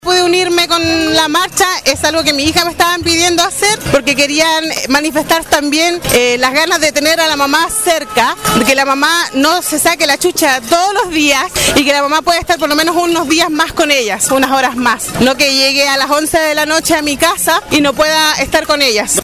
Una de las asistentes a la marcha, quien participó junto a su pequeña hija, habló con los micrófonos de Radio Sago, y contó que decidió ser parte de la convocatoria a modo de sembrar consciencia del poco tiempo que miles de madres pasan junto a sus hijos por temas de trabajo.